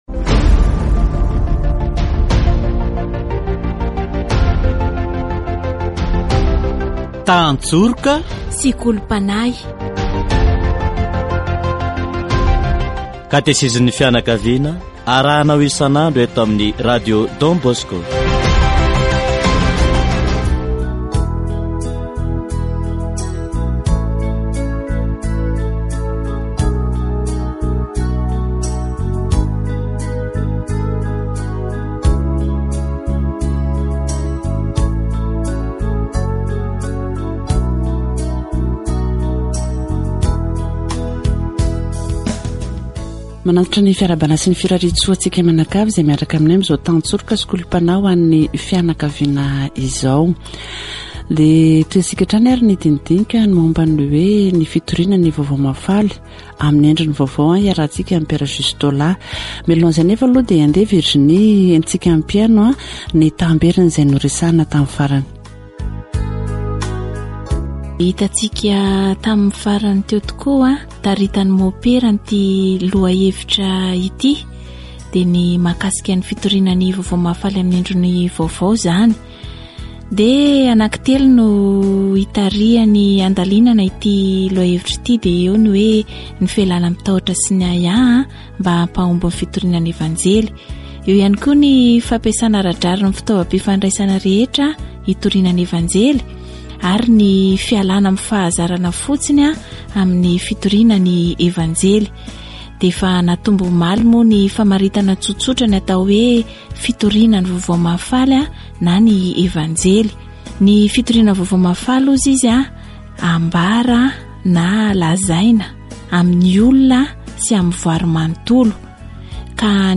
Catéchèse sur la nouvelle Evangélisation